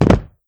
player_jumpland.wav